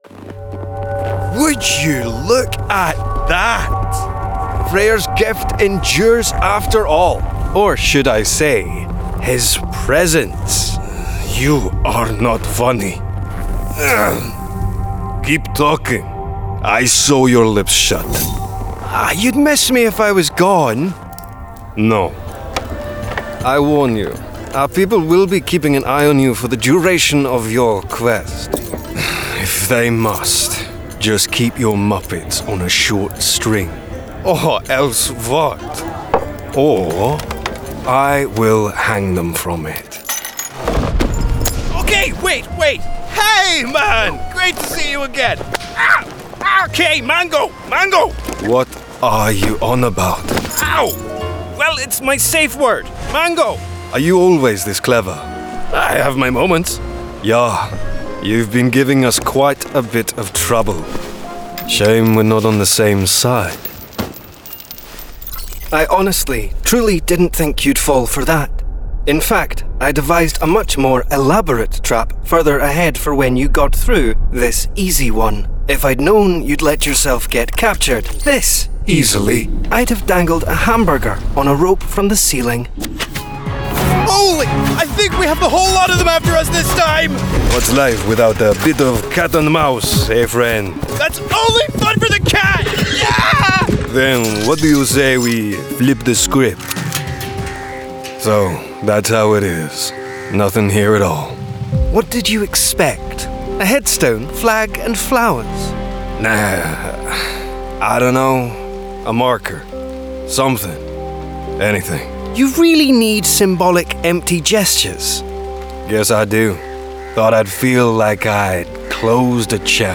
VG Reel
• Native Accent: Scottish
• Home Studio